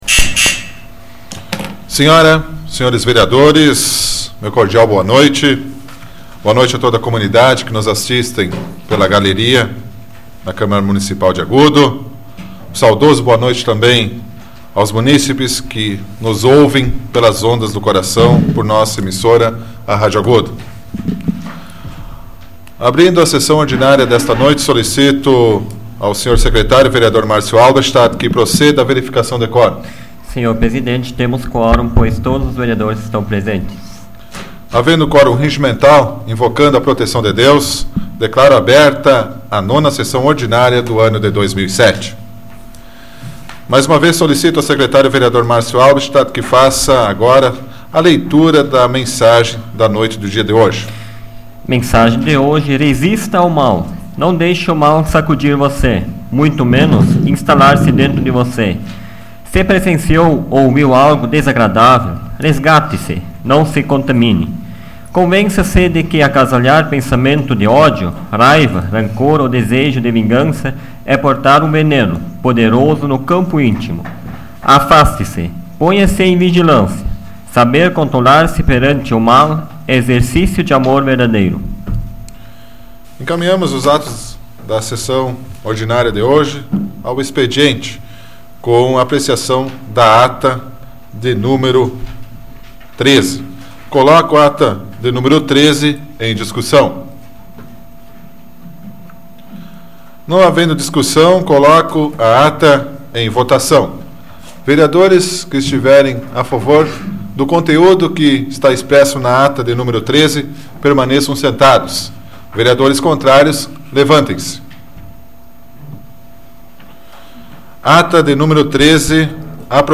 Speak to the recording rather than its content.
Áudio da 83ª Sessão Plenária Ordinária da 12ª Legislatura, de 30 de abril de 2007